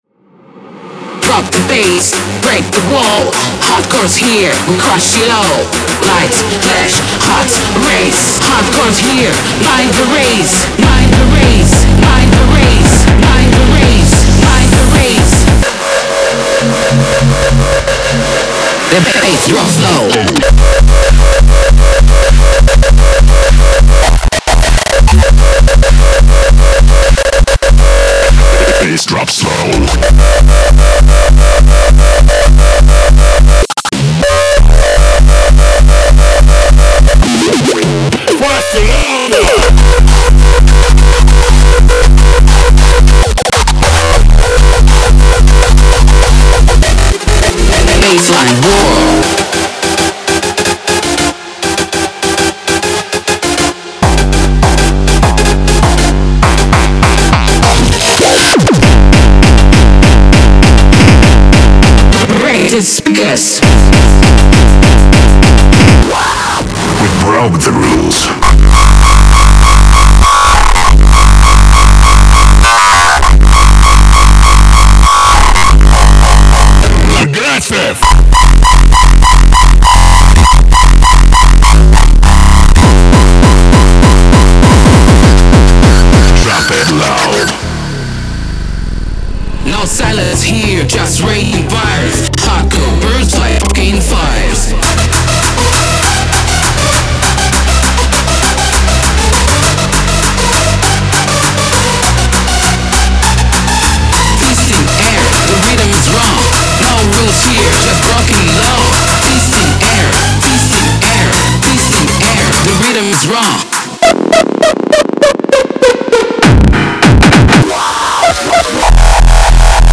Genre:Hardcore
怒涛のキック、アグレッシブなシンセ、強力なドラムエレメントが、今日の最もハードなサウンドの本質を捉えています。
BPM: 200-210 | 100% ロイヤリティフリー
デモサウンドはコチラ↓
66 Zaag Kick Loops
24 Oldschool Synth Loops (11 Dry, 13 Wet)
34 Over-Distorted Synth Fills
56 Vocal Shouts